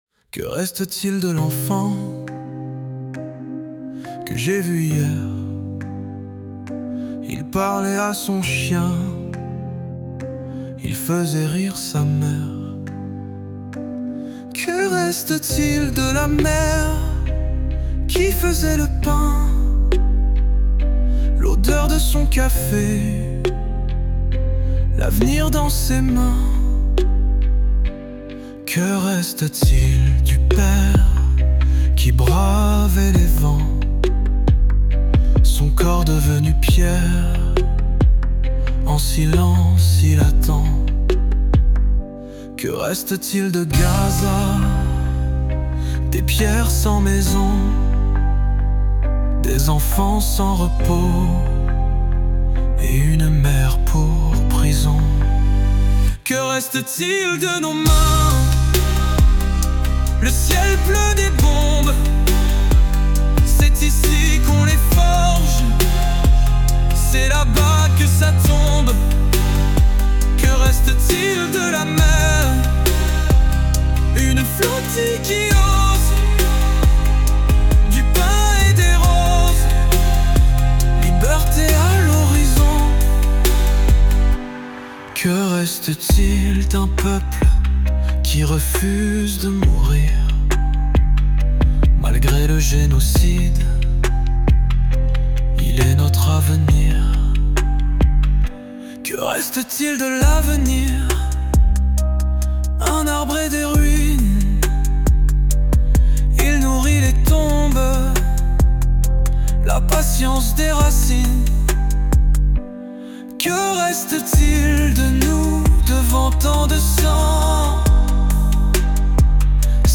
Chanson pour Gaza
Composée en cinq minutes par une IA, portée par des voix anonymes.